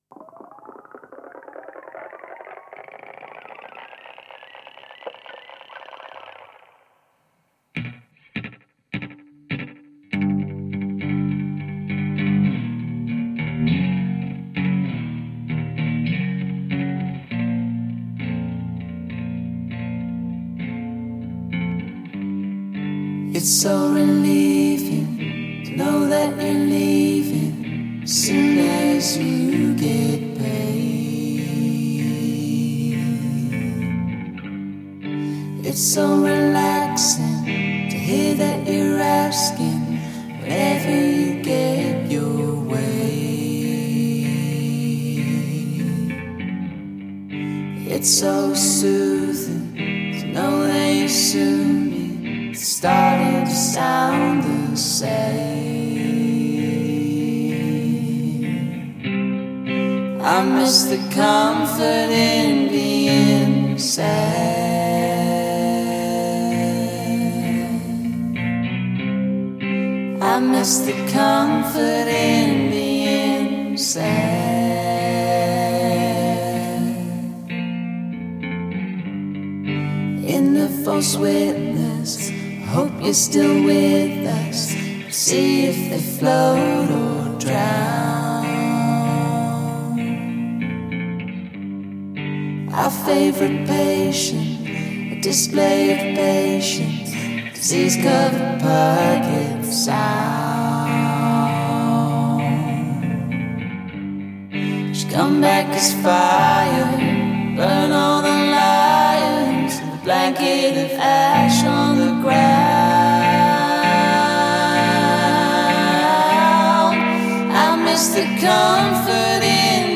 Και προφανώς το έφεραν πιο κοντά στο μουσικό τους στυλ.